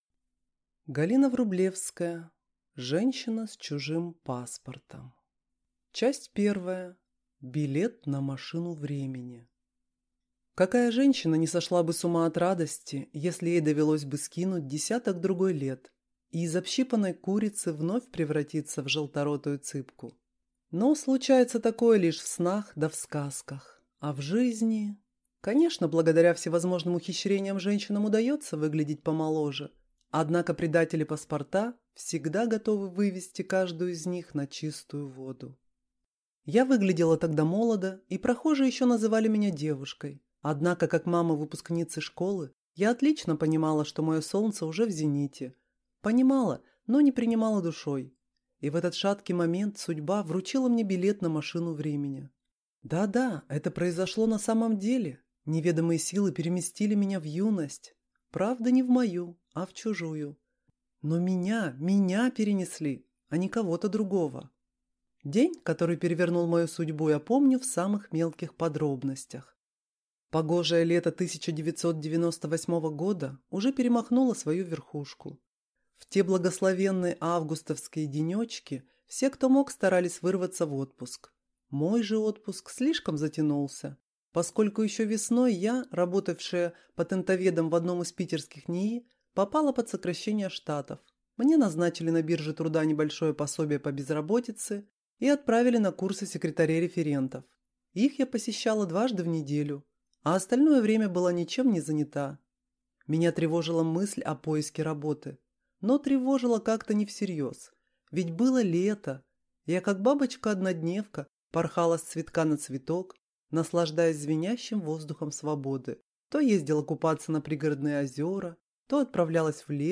Аудиокнига Женщина с чужим паспортом | Библиотека аудиокниг